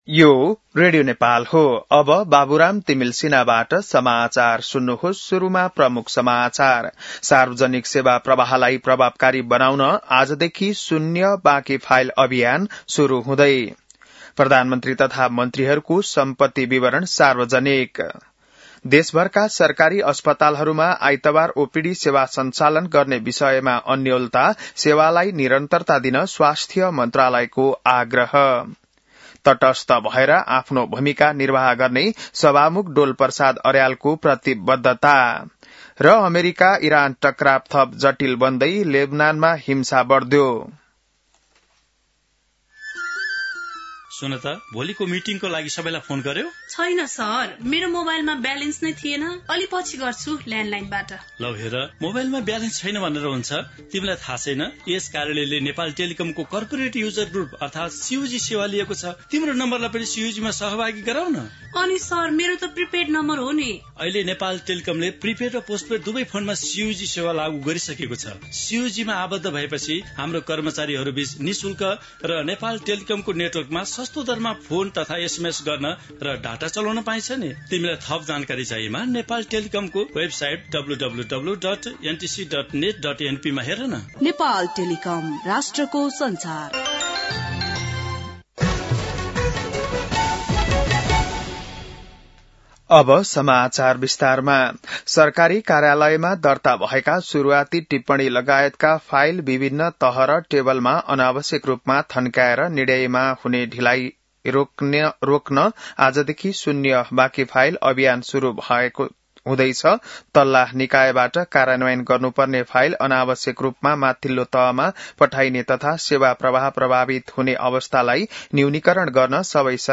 बिहान ७ बजेको नेपाली समाचार : ३० चैत , २०८२